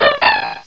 cry_not_bonsly.aif